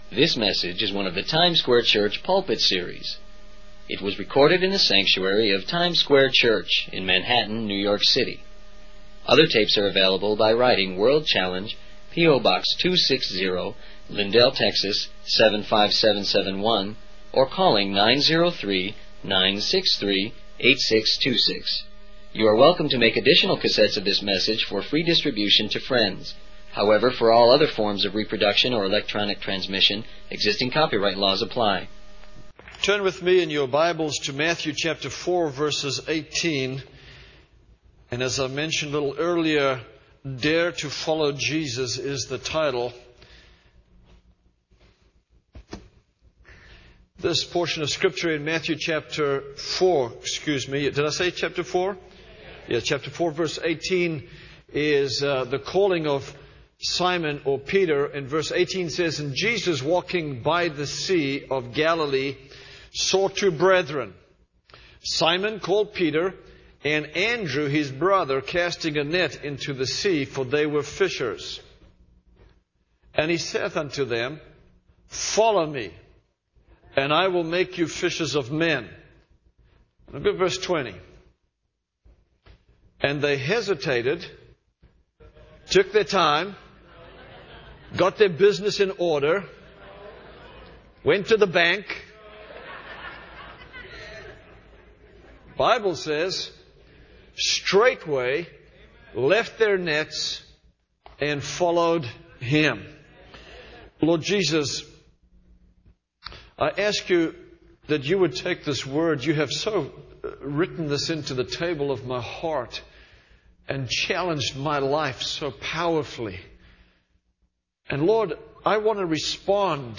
In this sermon, titled 'Dare to Follow Jesus,' the preacher focuses on the calling of Simon Peter and Andrew by Jesus. He emphasizes the urgency and importance of responding to God's call immediately, without hesitation.